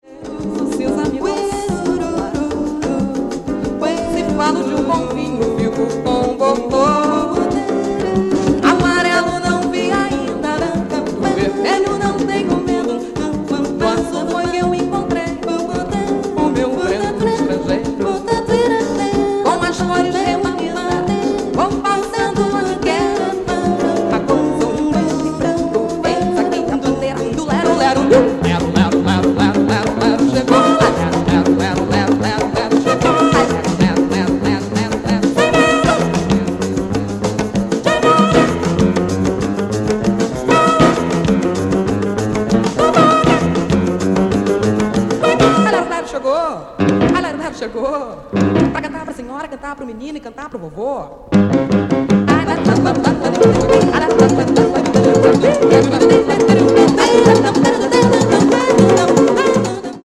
Brazilian jazz